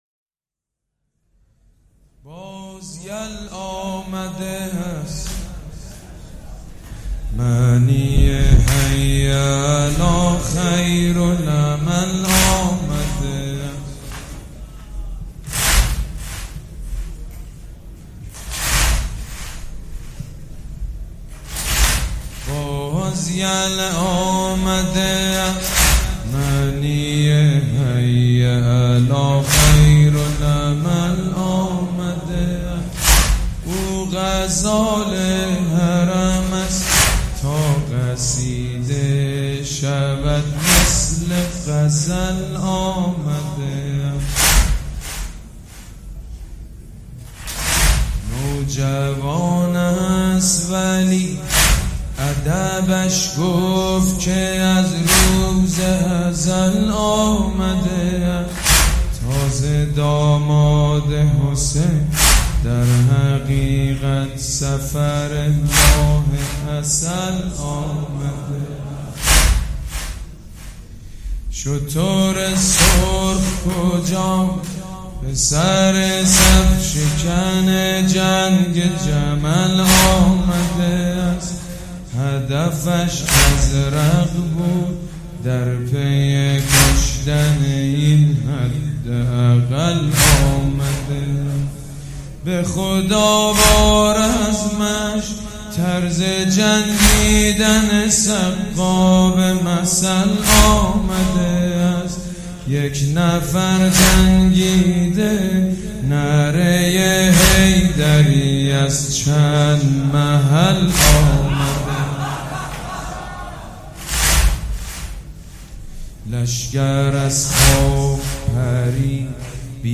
مداحی جدید حاج سید مجید بنی فاطمه حسینیه ی ریحانه الحسین شب ششم محرم97